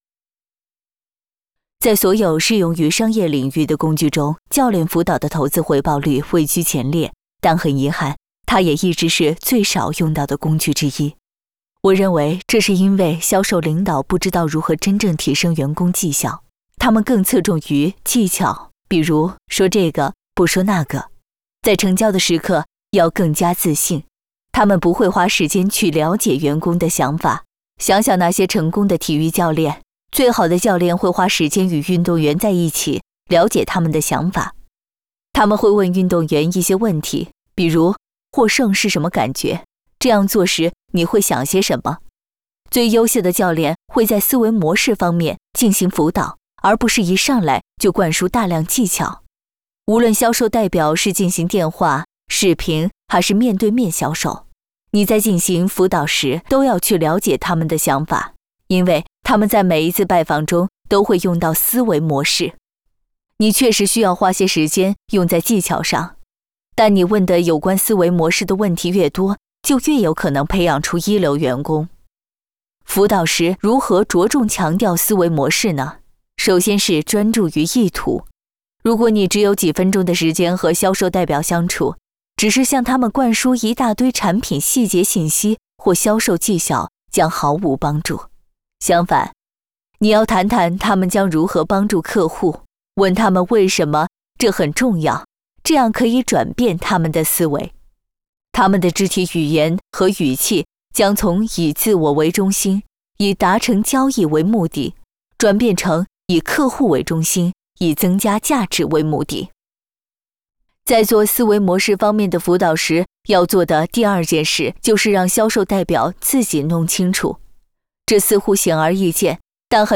Chinese_Female_018VoiceArtist_8Hours_High_Quality_Voice_Dataset